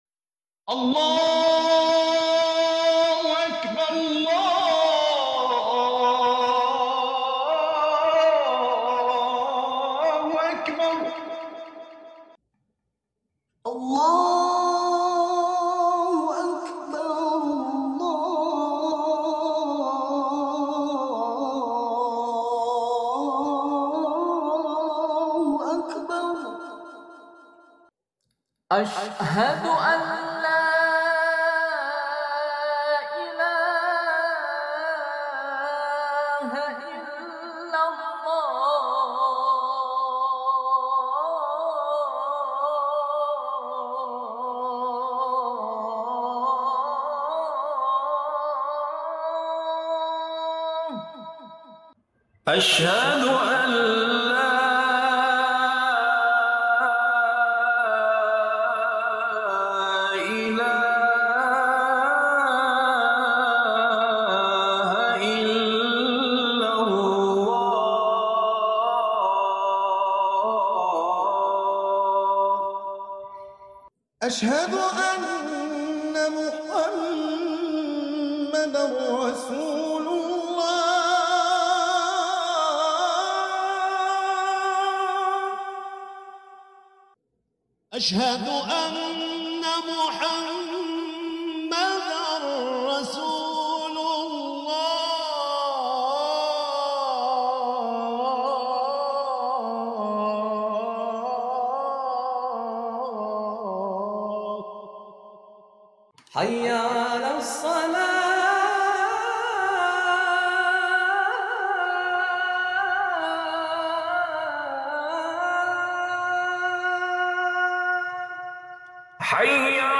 Grand Final Contest Azan 2025 Sound Effects Free Download